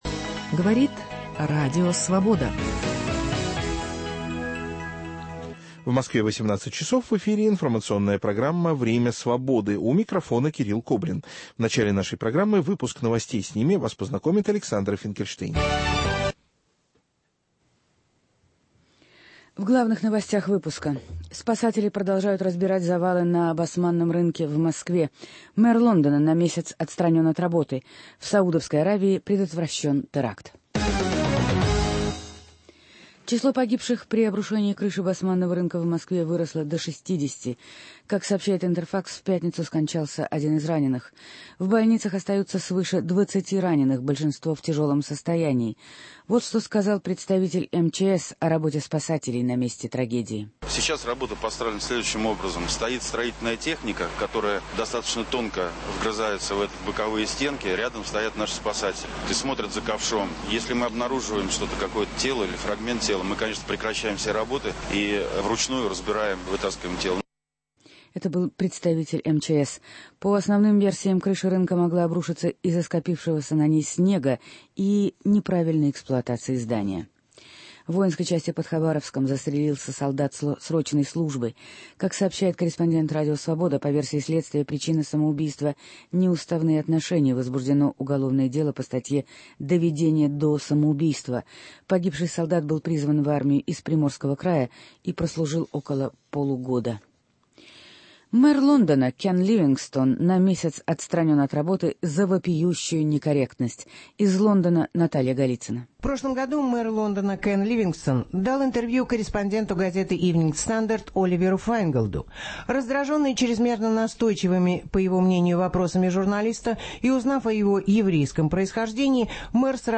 Пресс-конференция главы ведомства ООН по правам человека Луизы Арбур